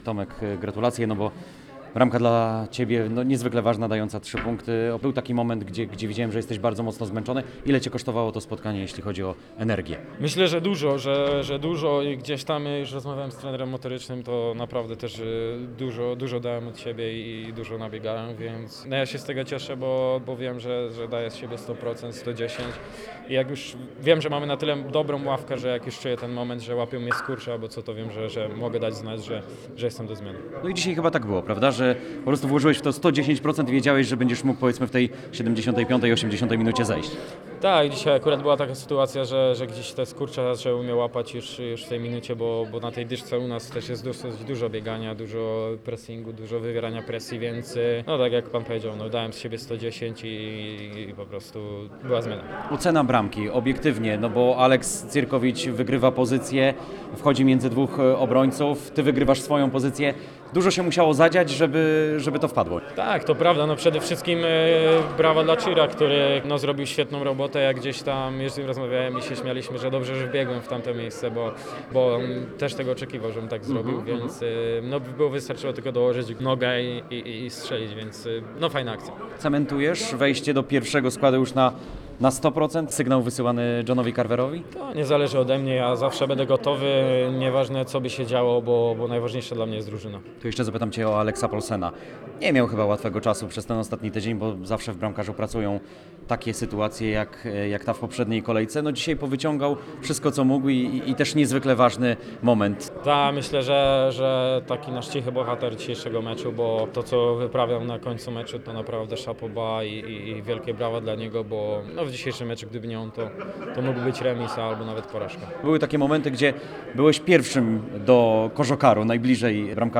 – Jak już czuję ten moment, że łapią mnie skurcze, to wiem, że mamy na tyle dobrą ławkę, że mogę dać znać, że jestem do zmiany – mówił przed naszym mikrofonem po spotkaniu.